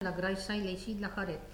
Localisation Saint-Jean-de-Monts
Catégorie Locution